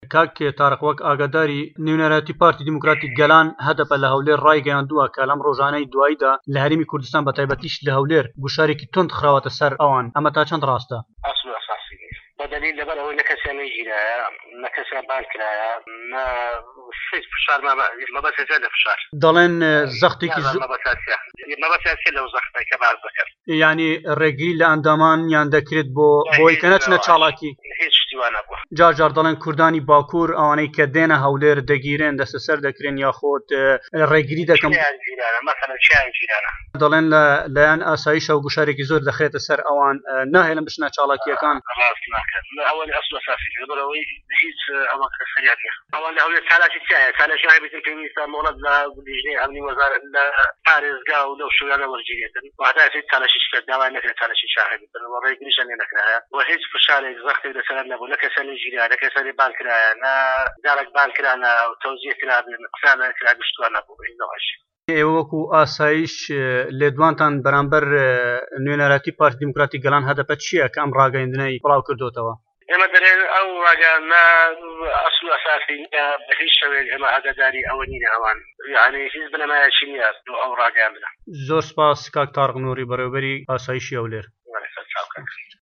تاریق نووری بەڕێوەبەری ئاسایشی هەولێر له‌ وتووێژێكدا له‌گه‌ڵ به‌شى كوردى ده‌نگى ئه‌مه‌ريكا ده‌ڵێت ئەو ڕاگەیاندنەی هەدەپە ئه‌سڵ و ئه‌ساسى نیيه‌ به‌ ده‌ليل نه‌ كه‌سيان گيراوه‌ و نه‌ كه‌سيان بانگهێشتكراوه‌ هيچ گوشارێك له‌ سه‌ر ئه‌وانيش نيیه‌ مه‌به‌ستيان چيه‌ له‌ فشار.